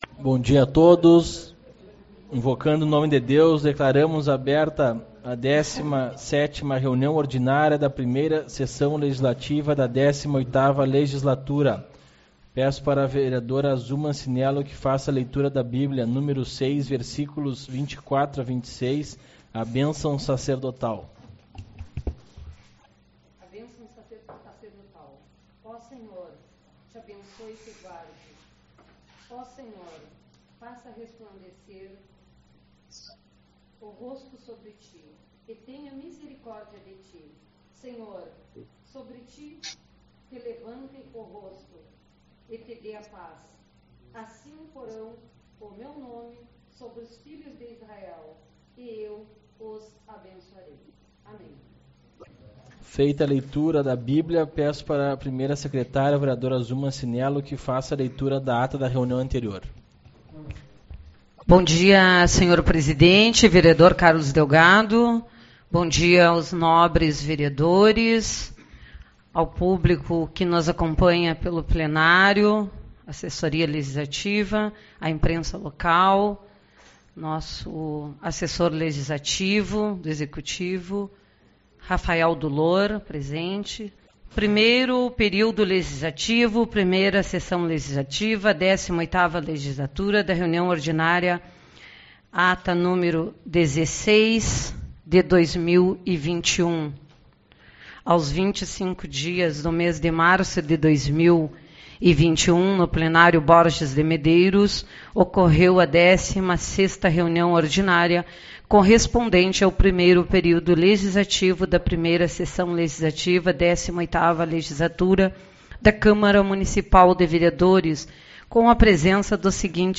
30/03 - Reunião Ordinária